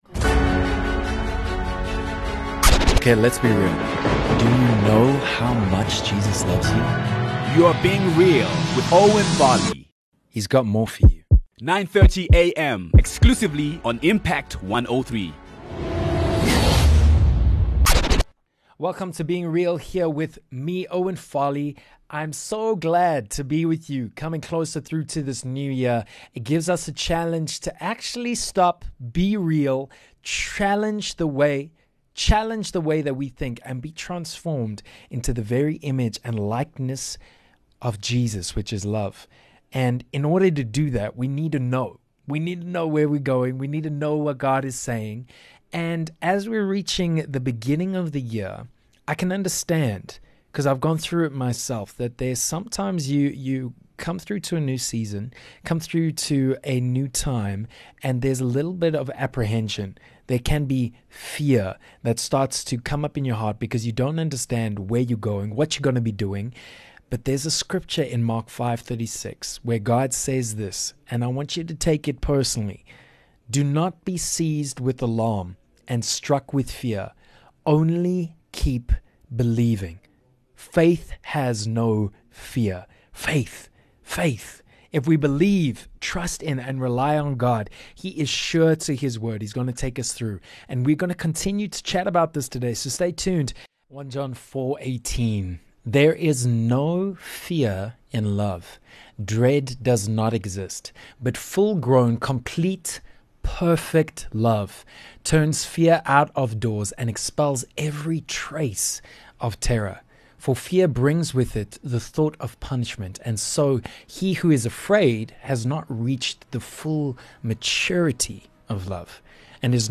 This episode is all testimony!! Build your faith up as you listen to how Gods Love changes everything.